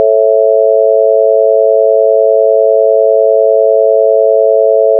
mysinewave.wav